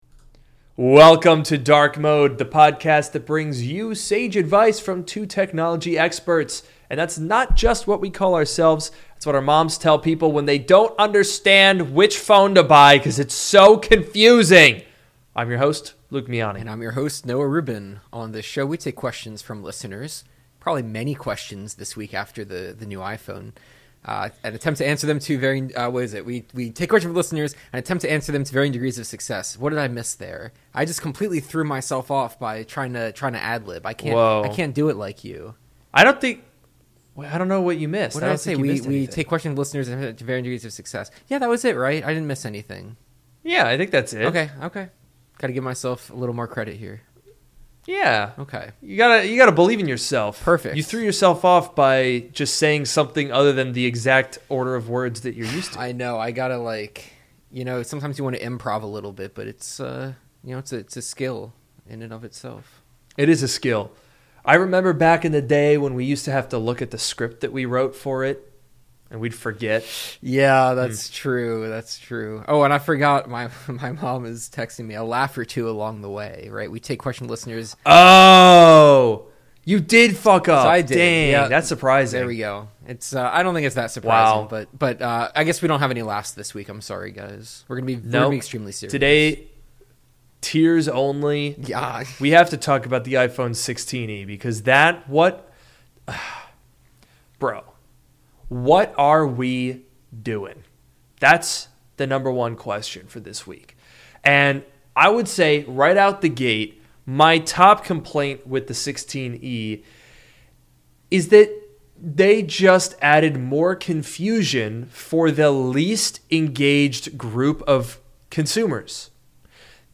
This is Drk Mode, the podcast that brings you sage advice from two technology experts.